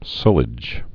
(sŭlĭj)